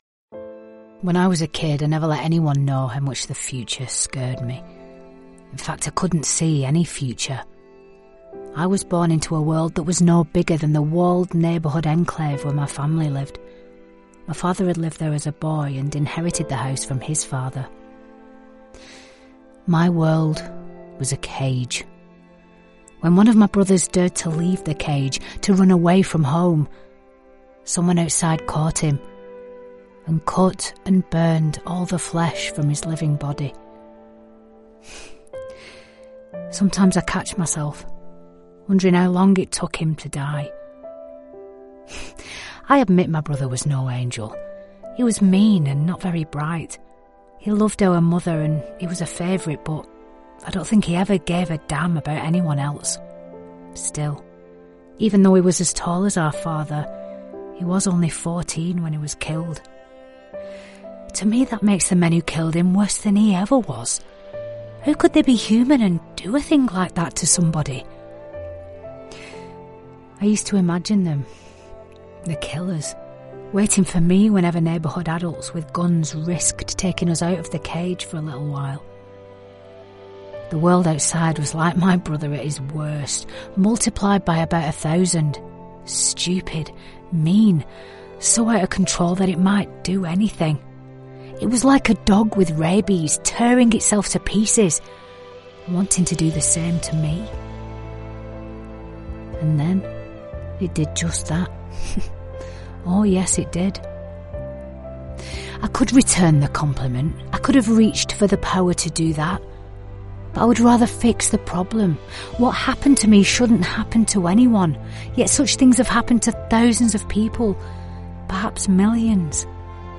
Fast, reliable, and naturally conversational, she delivers professional voice over that connects and compels.
Audiobooks
* Purpose built, isolated, acoustically treated sound booth
* Rode NT1-A Mic & pop shield